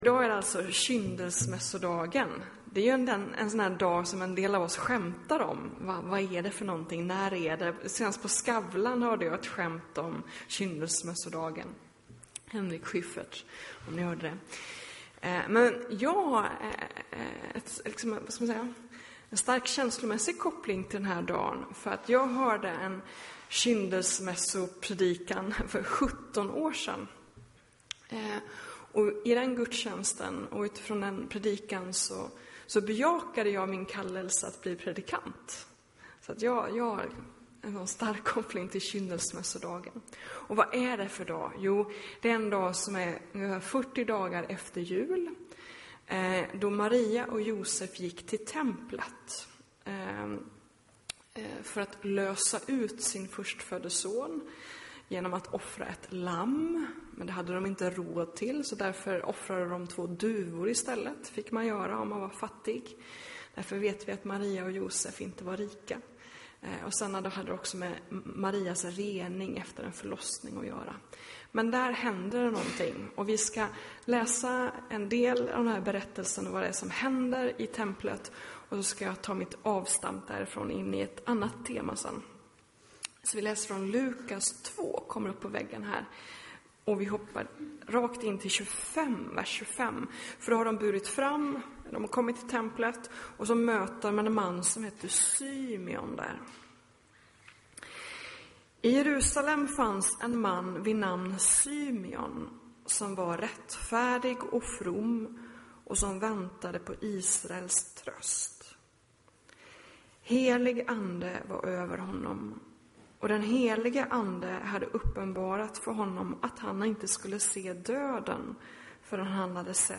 predikar